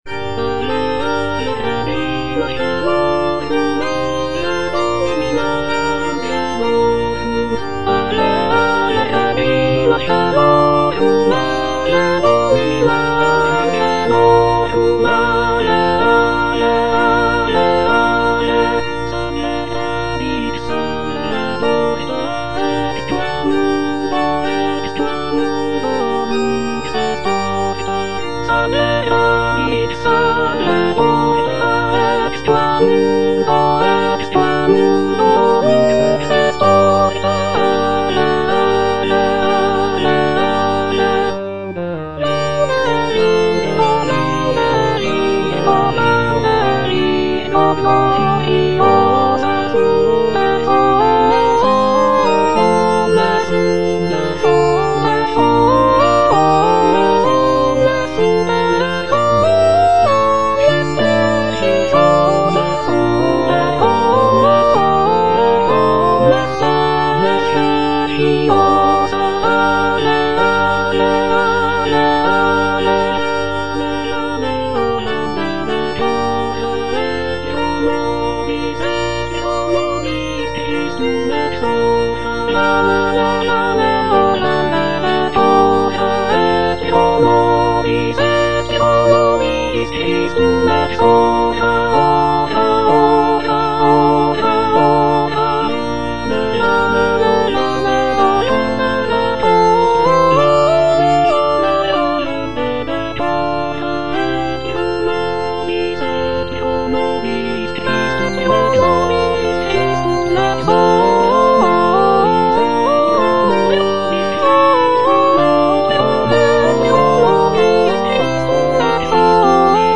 I. LEONARDA - AVE REGINA CAELORUM Soprano (Emphasised voice and other voices) Ads stop: auto-stop
"Ave Regina caelorum" is a sacred vocal work composed by Isabella Leonarda, a 17th-century Italian composer and nun. The piece is a hymn dedicated to the Virgin Mary, often sung during the season of Lent.